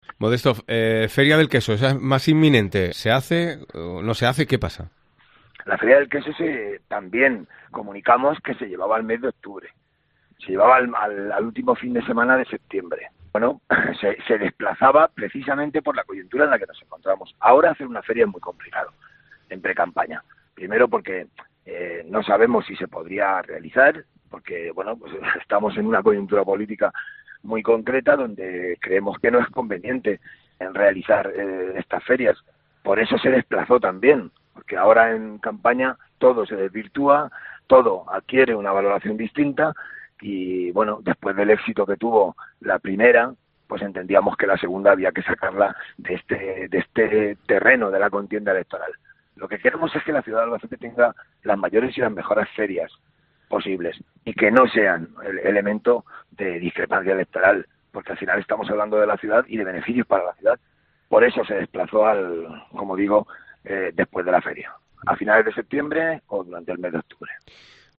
El concejal de Comercio y Promoción, en declaraciones a COPE- Albacete nos ha contado el motivo de cambio de fechas para esta segunda Feria del Queso, y no es otro que la contienda electoral que se aproxima. Modesto Belinchón no cree conveniente celebrar la Feria durante la campaña electoral.